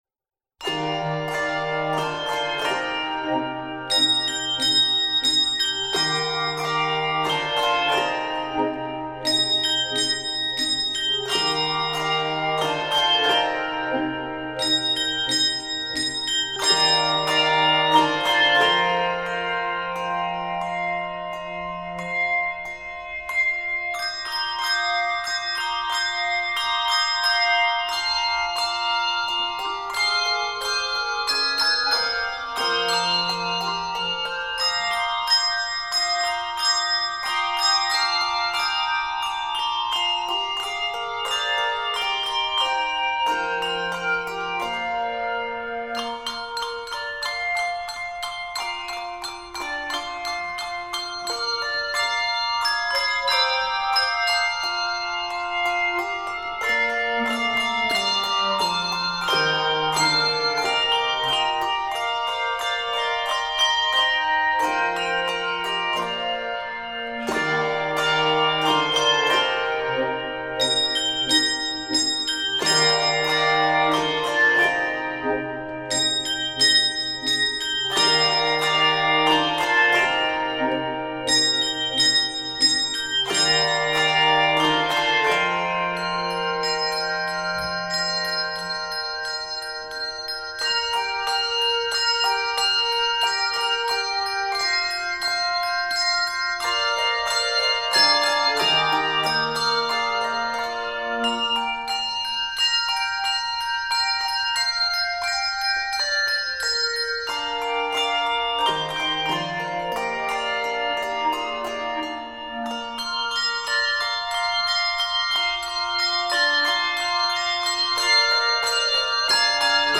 Keys of Bb Major and C Major.
Composer: Traditional Spiritual
Octaves: 3-5